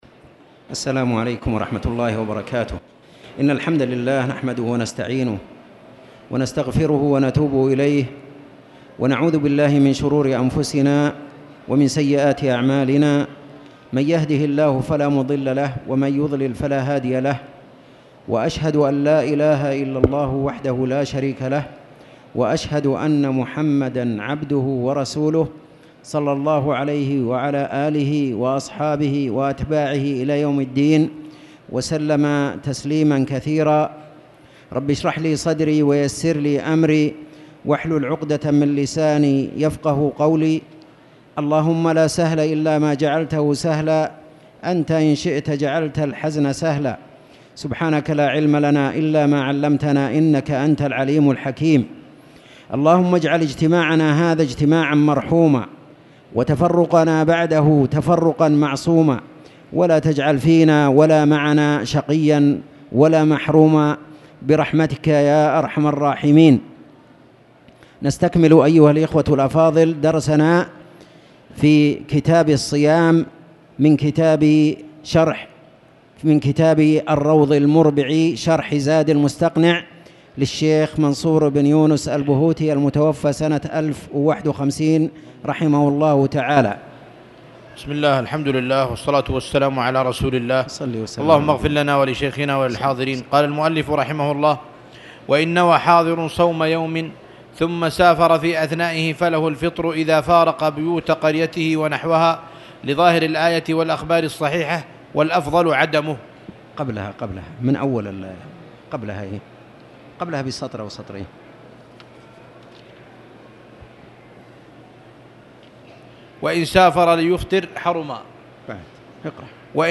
تاريخ النشر ١٤ ربيع الأول ١٤٣٨ هـ المكان: المسجد الحرام الشيخ